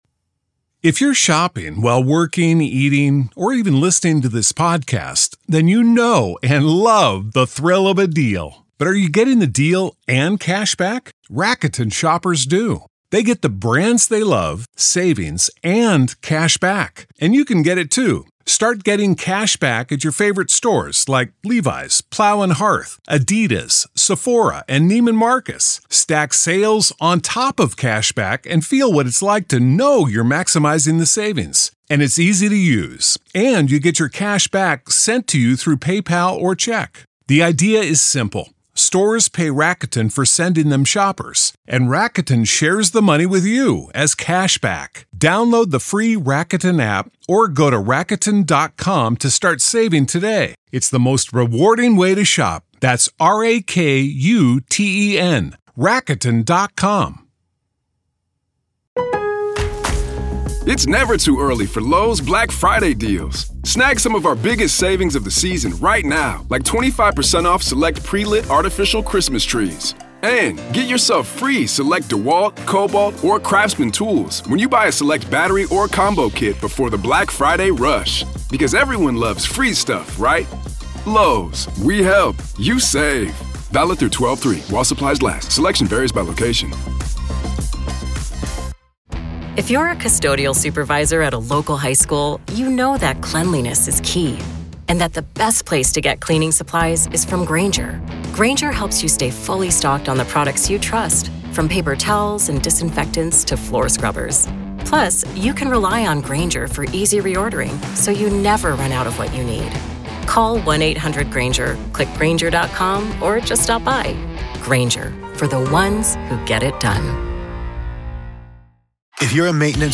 This interview digs into why these inconsistencies matter — not emotionally, but legally.